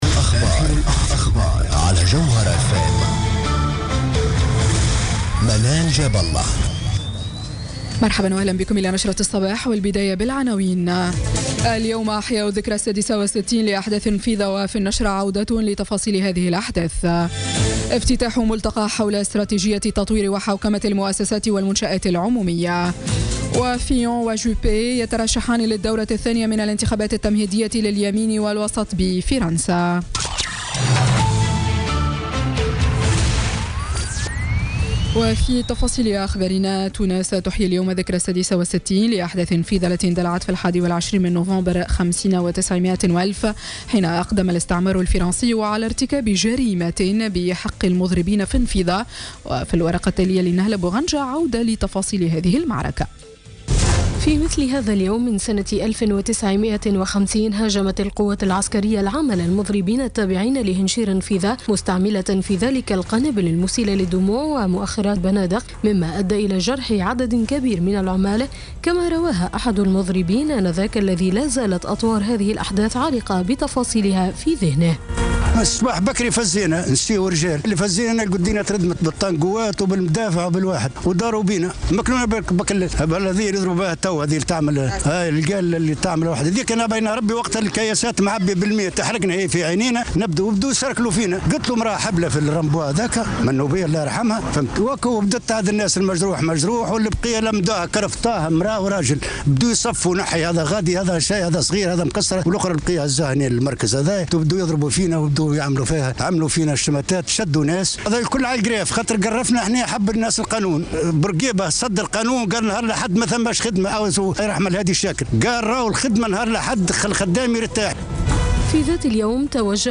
نشرة أخبار السابعة صباحا ليوم الإثنين 21 نوفمبر 2016